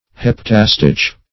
Search Result for " heptastich" : The Collaborative International Dictionary of English v.0.48: Heptastich \Hep"ta*stich\, n. [Hepta- + Gr. sti`chos line, verse.]